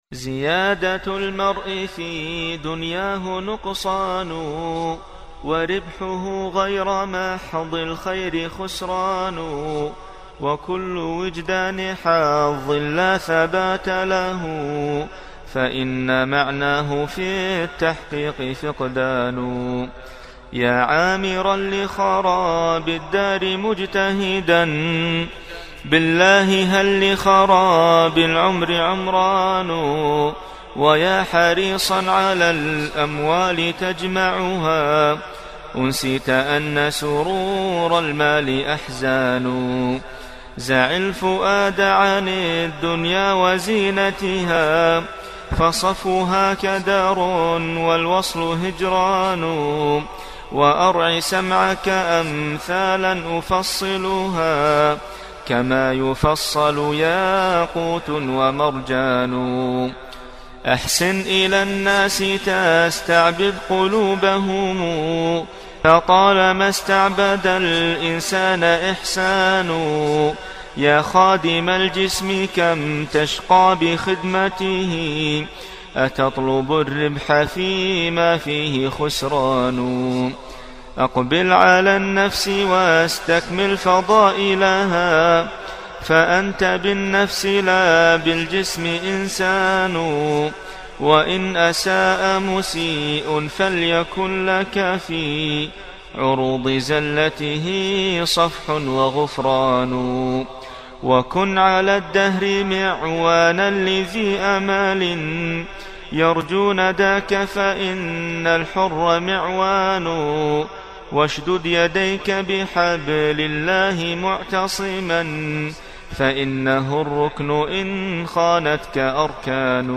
حداء